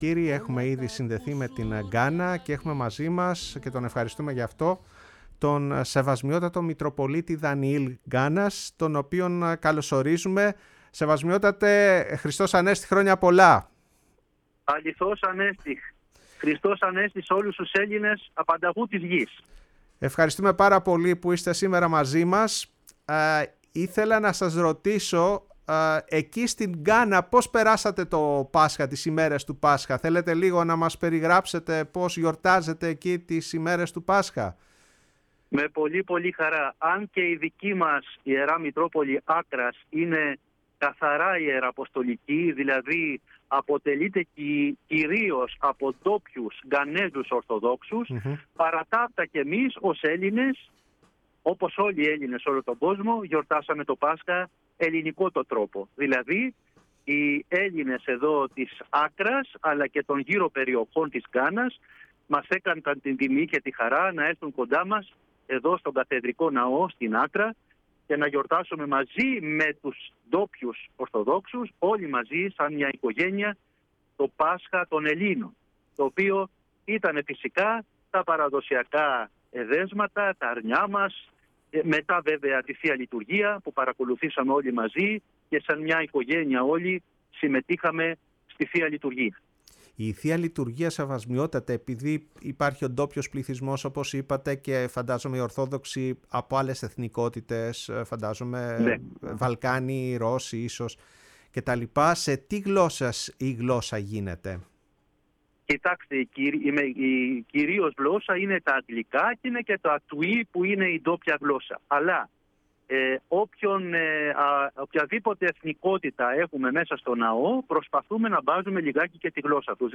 Η ΦΩΝΗ ΤΗΣ ΕΛΛΑΔΑΣ Η Παγκοσμια Φωνη μας ΟΜΟΓΕΝΕΙΑ ΣΥΝΕΝΤΕΥΞΕΙΣ Συνεντεύξεις ΑΙΘΙΟΠΙΑ Ερυθραια Ζαμπια Μαλαουι Μητροπολιτης Γκανας κ. Δανιηλ Μοζαμβικη Πασχα